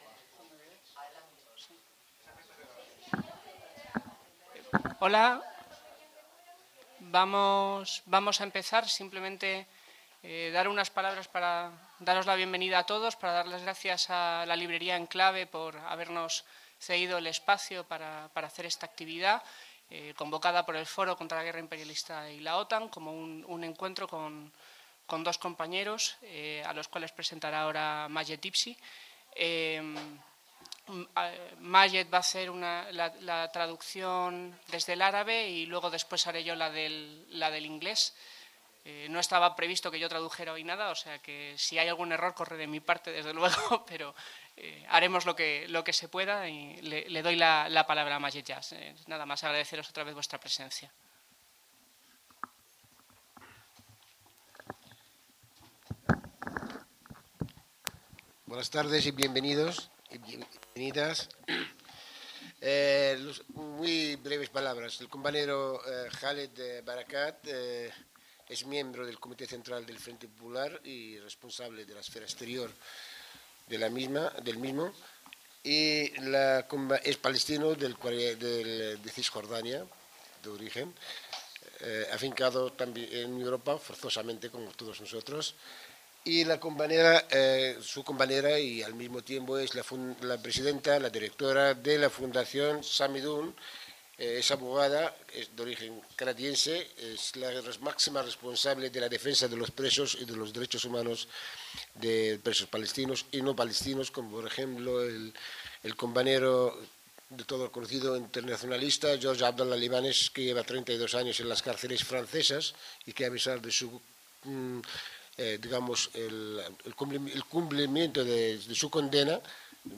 Crónica del acto organizado por el Foro contra la guerra imperialista y la OTAN sobre la Campaña por la liberación de los prisioneros palestinos: